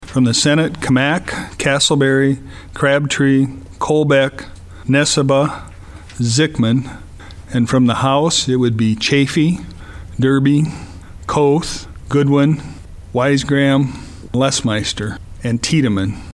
Bartels shared the names of those appointed to the Study Committee on Property Tax Structure and Tax Burden.